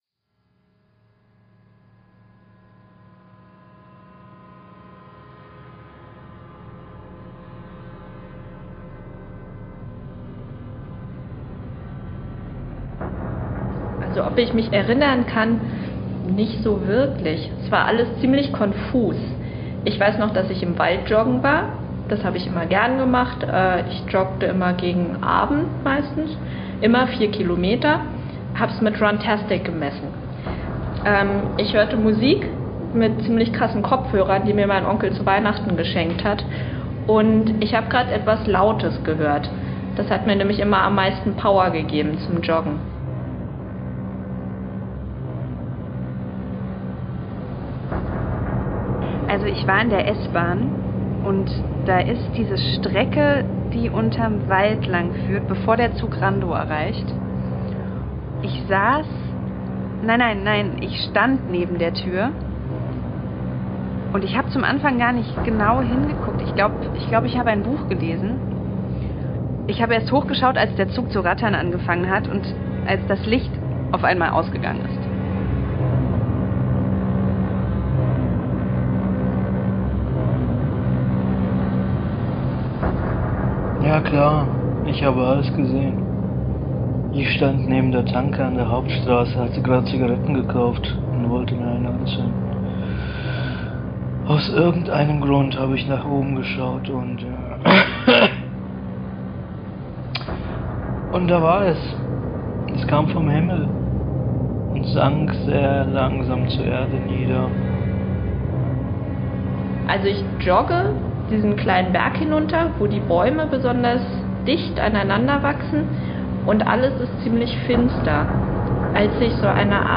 Musik: Iszoloscope